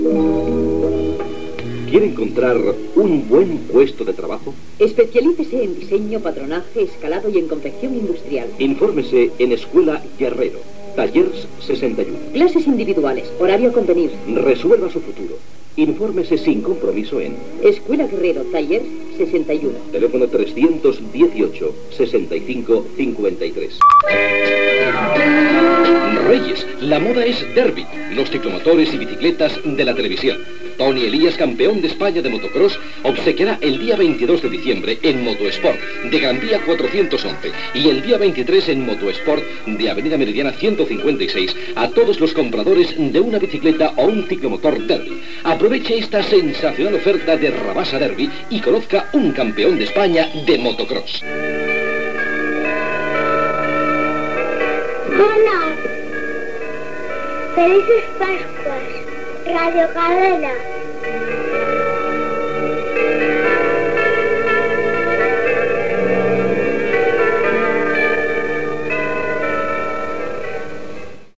Publicitat i desig de bon nadal des de Radiocadena